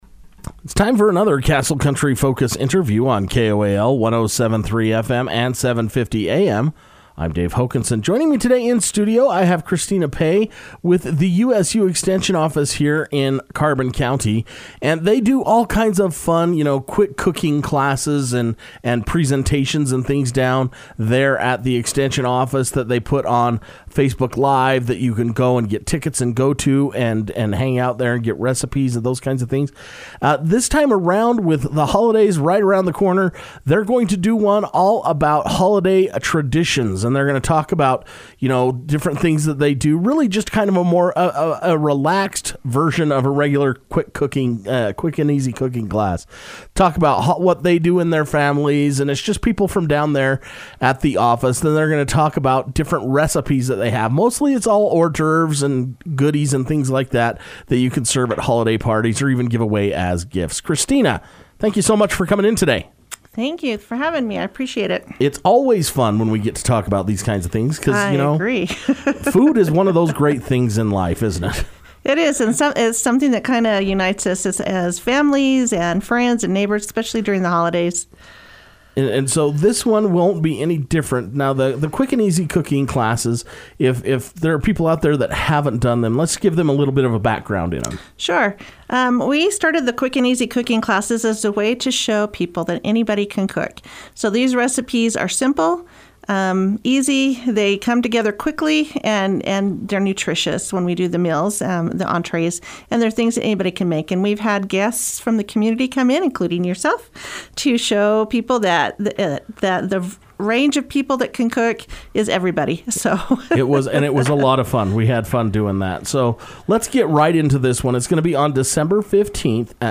took time to come to Castle Country Radio to discuss all the details.